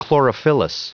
Prononciation du mot chlorophyllous en anglais (fichier audio)
Prononciation du mot : chlorophyllous